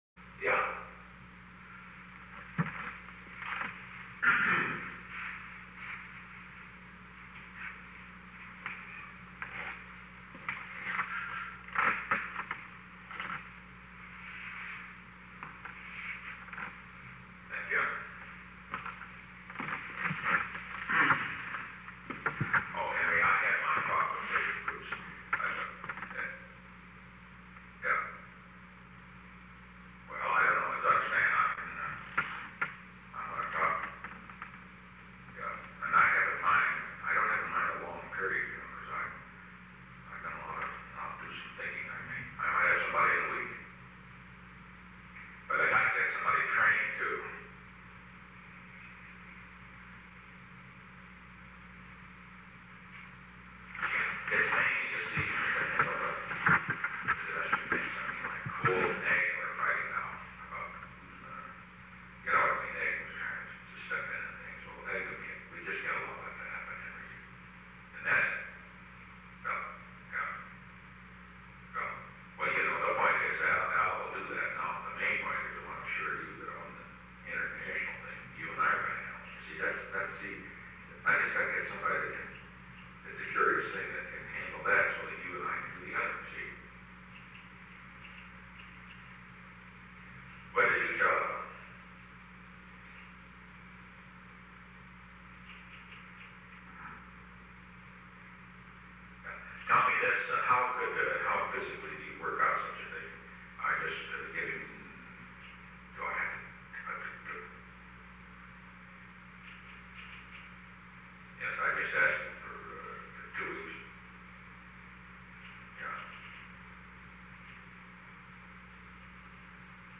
Conversation: 911-012
Recording Device: Oval Office
On May 3, 1973, President Richard M. Nixon and Henry A. Kissinger met in the Oval Office of the White House at an unknown time between 10:21 am and 10:30 am. The Oval Office taping system captured this recording, which is known as Conversation 911-012 of the White House Tapes.
The President talked with Henry A. Kissinger.